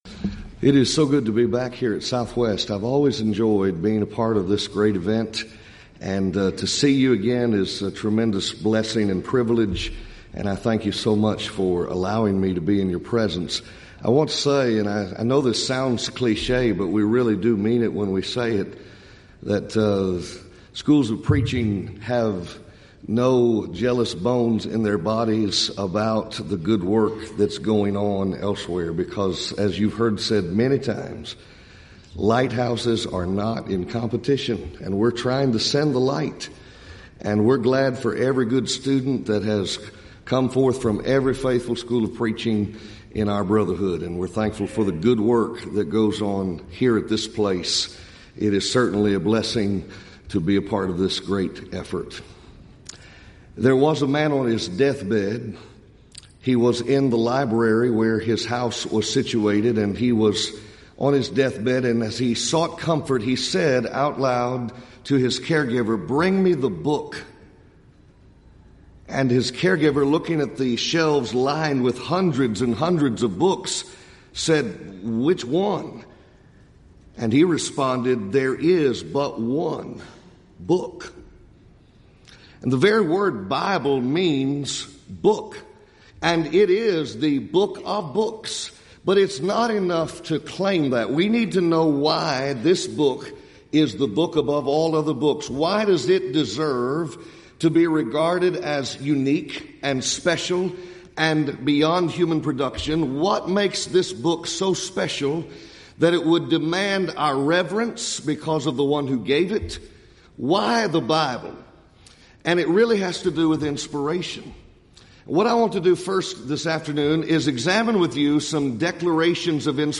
Event: 30th Annual Southwest Bible Lectures
lecture